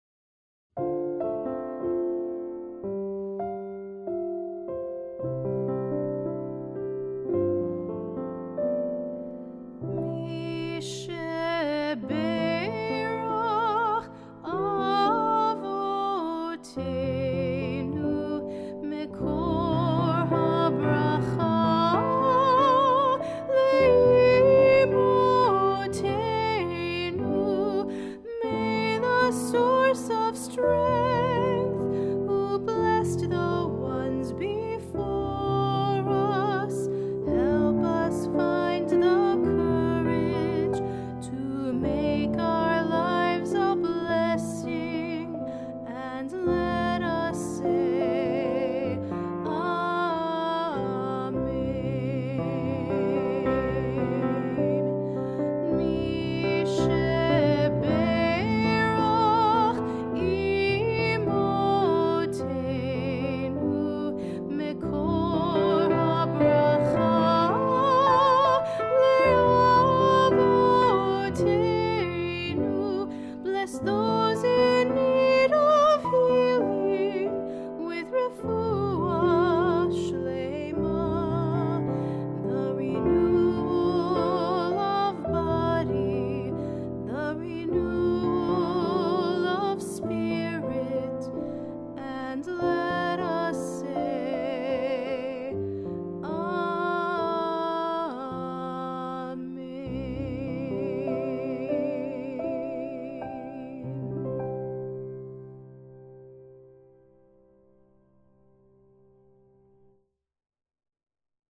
Mi Shebeirach, the prayer for healing, is sung at most services.